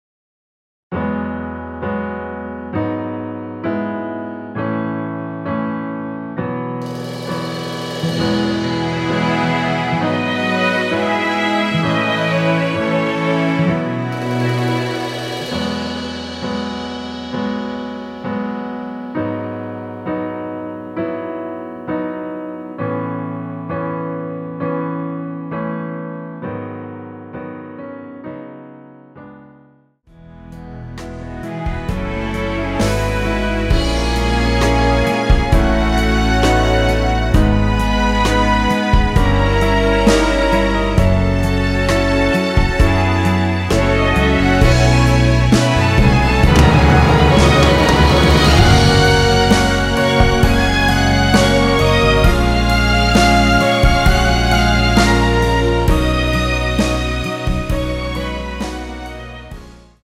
원키에서(-1)내린 MR입니다.
앞부분30초, 뒷부분30초씩 편집해서 올려 드리고 있습니다.
중간에 음이 끈어지고 다시 나오는 이유는